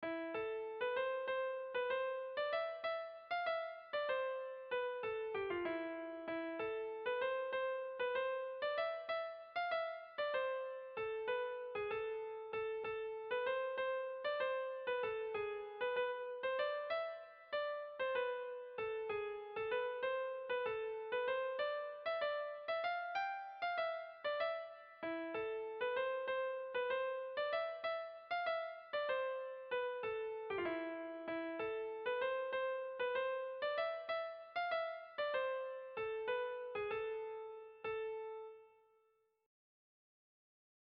Tragikoa
A1A2BDA1A2